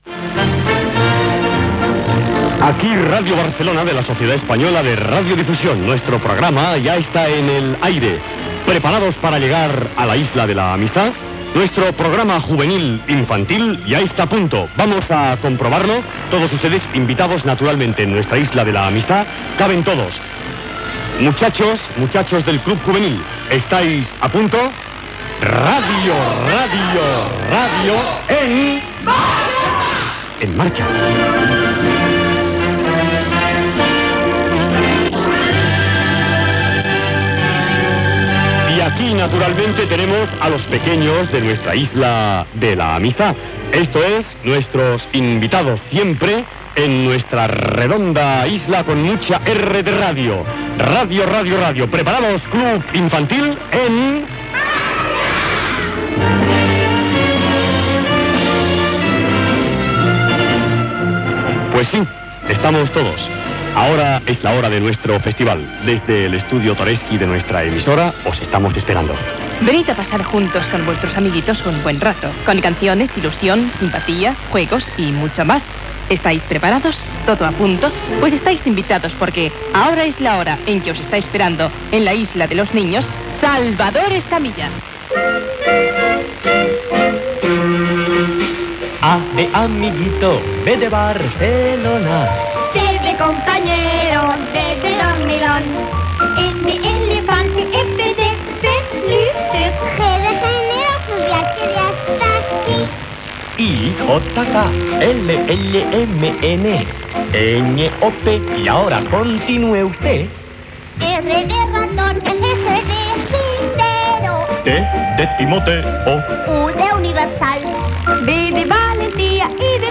Salutació inicial, amb la identificació. Cançons del programa en castellà i català.
Infantil-juvenil